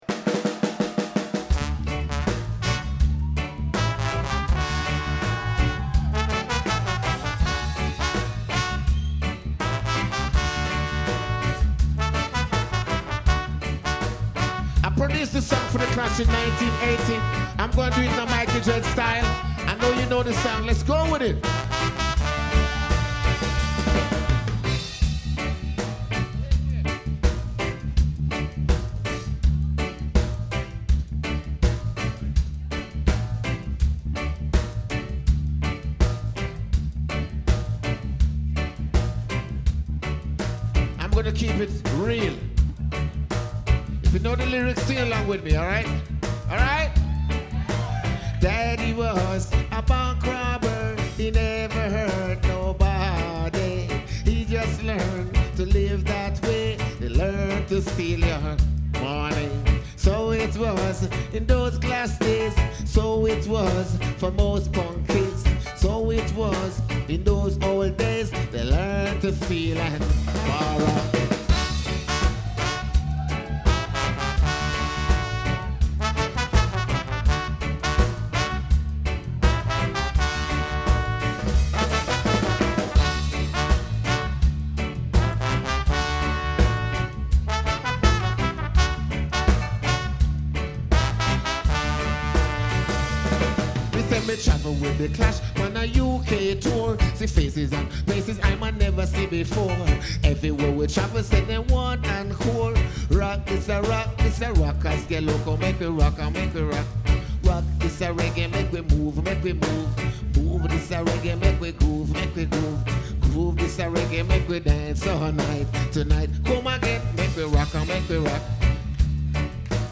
West End Cultural Centre, Winnipeg MB - September 16, 2006